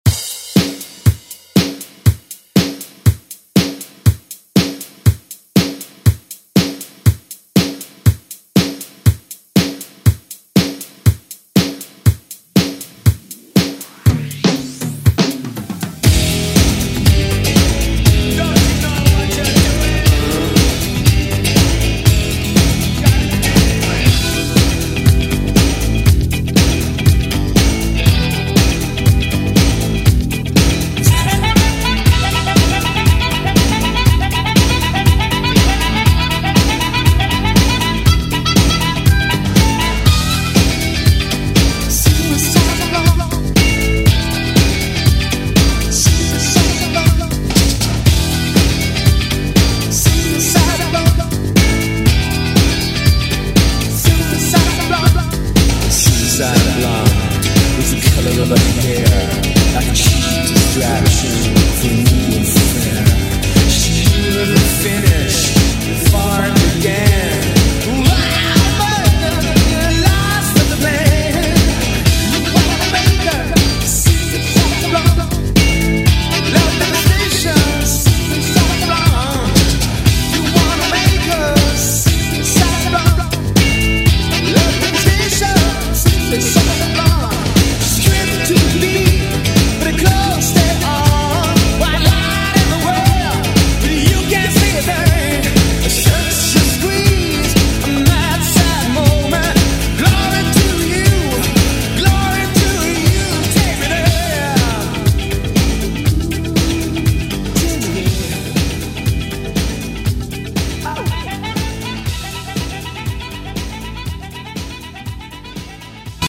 Genre: 90's